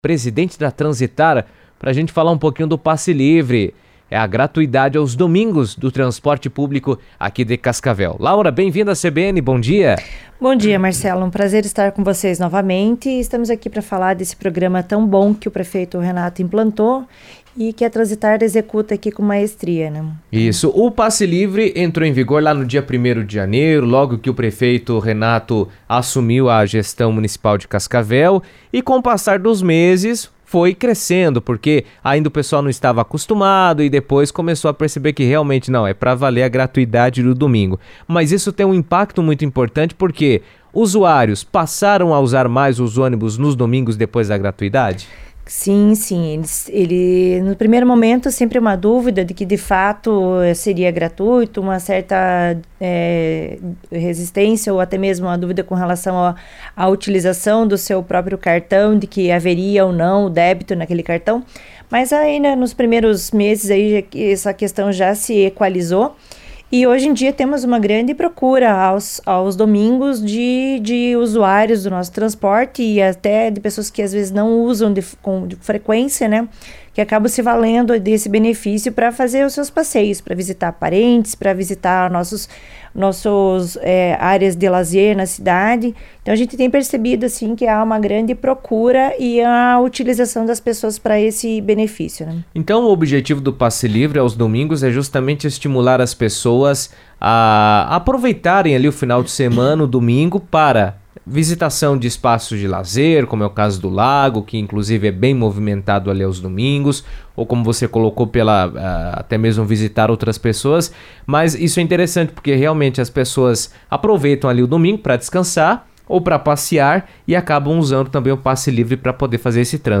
O município de Cascavel passou a oferecer passe livre no transporte coletivo aos domingos, uma medida que visa facilitar o deslocamento da população e incentivar o uso do transporte público. Em entrevista à CBN, Laura Rossi Leite, presidente da Transitar, explicou os critérios para o benefício, destacou os impactos positivos para a mobilidade urbana e ressaltou que a medida busca garantir mais acessibilidade e comodidade aos moradores da cidade.